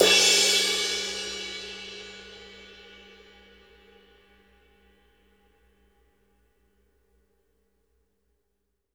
CRASH B   -R.wav